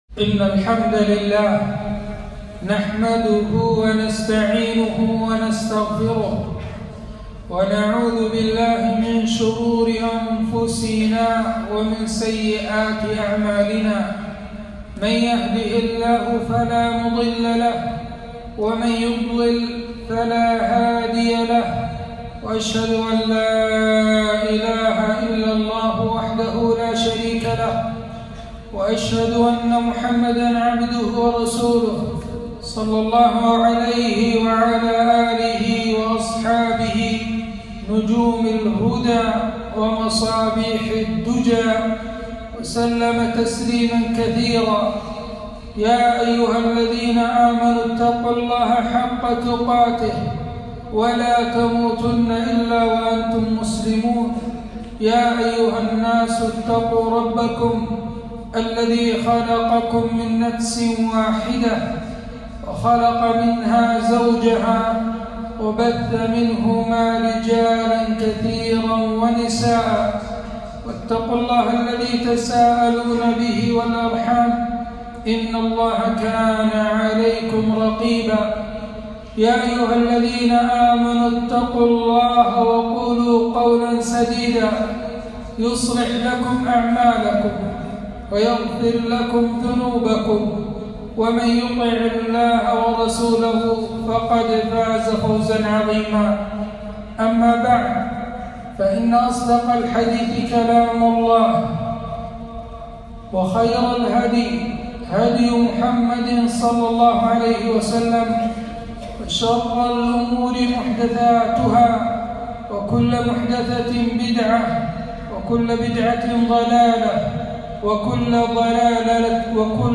خطبة - فضل الصلاة وأحوال الناس فيها